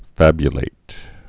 (făbyə-lāt)